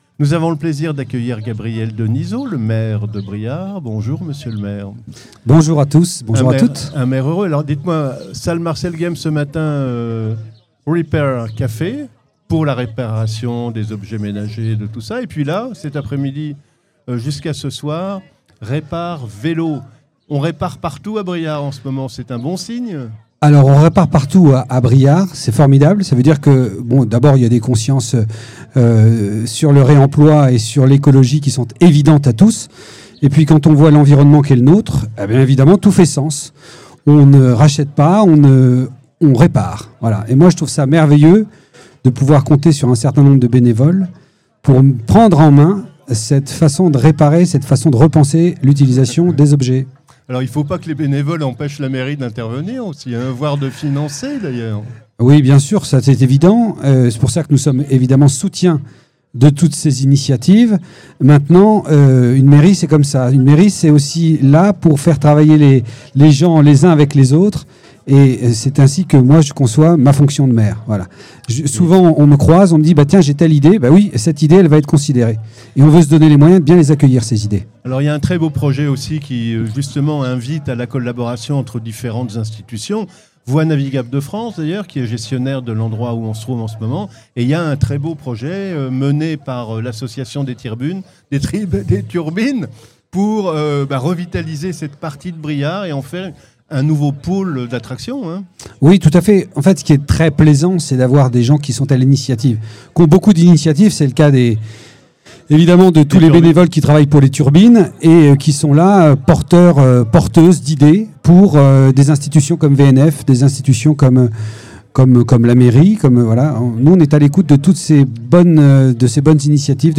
Interview - Gabriel Denizot - Maire de Briare
À l’occasion du Grand Répar Vélo des Turbines, Studio 45 vous propose une série d’interviews réalisées en direct du Pont Canal de Briare.